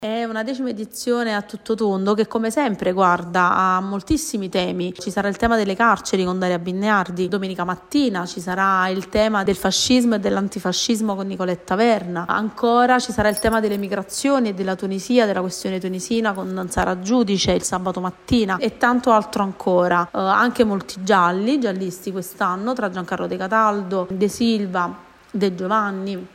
sonoro-divertito.mp3